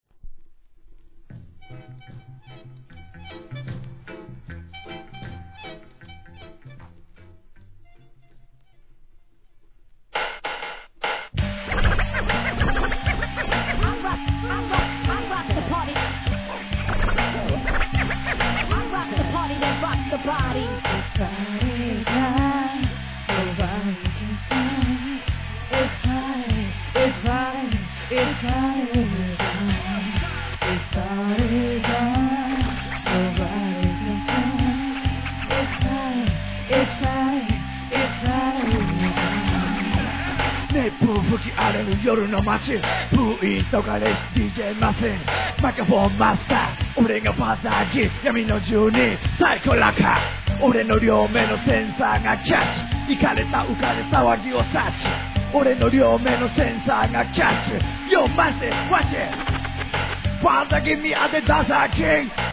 1. JAPANESE REGGAE